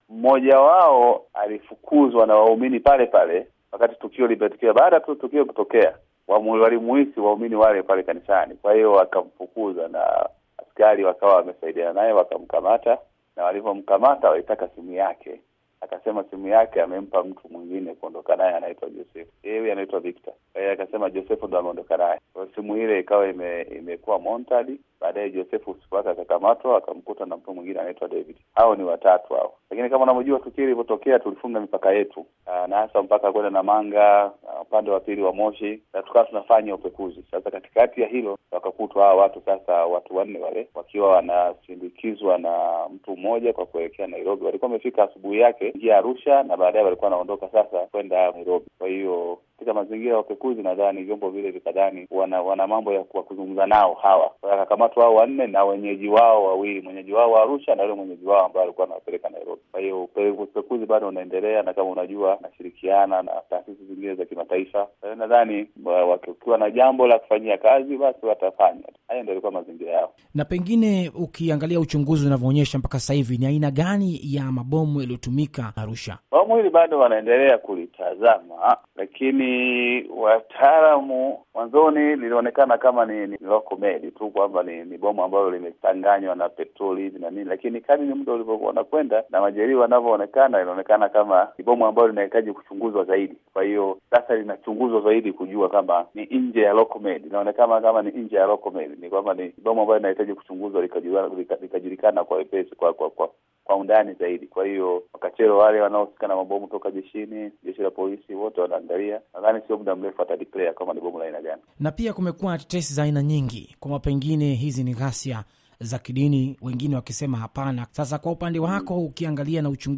Mahojiano na mkuu wa mkoa wa Arusha, Magesa Mulongo - 4:31